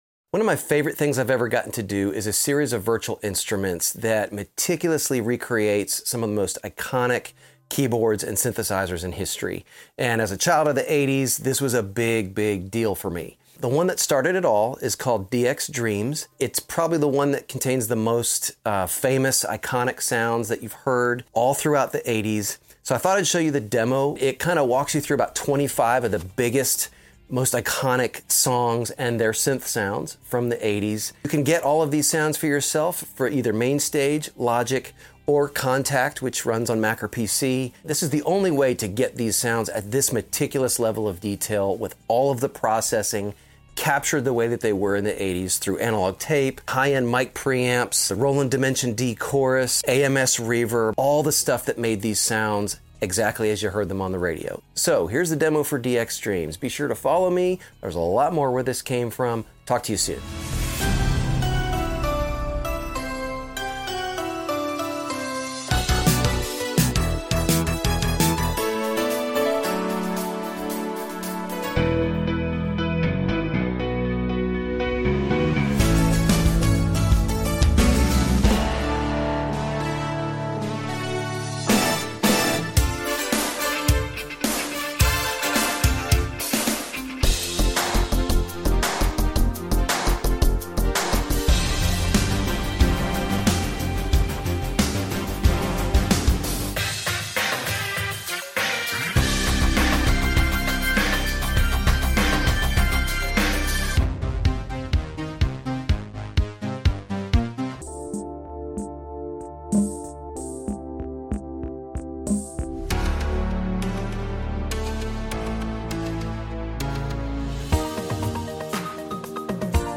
Best synth sounds of the sound effects free download
Best synth sounds of the 80s, part 1: Yamaha DX series.